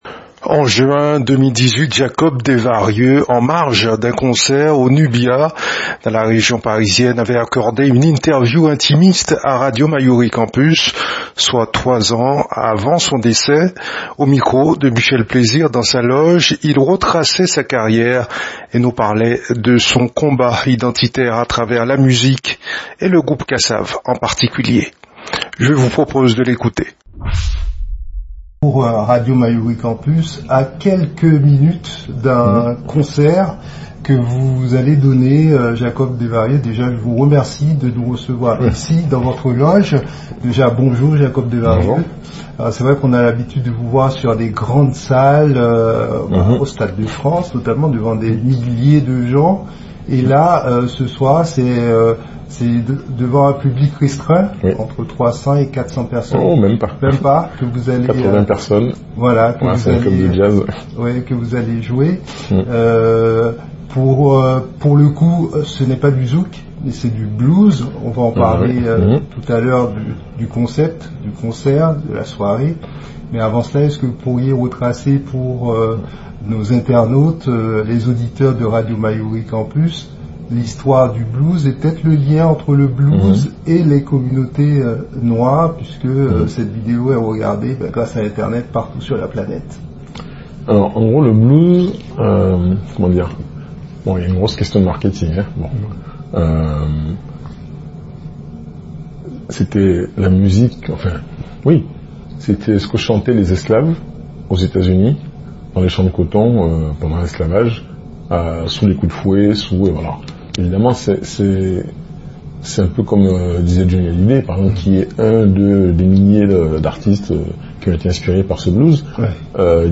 En Juin 2018, Jacob Desvarieux, en marge d'un concert au Nubia dans la région parisienne, avait accordé une interview intimiste à Radio Mayouri Campus.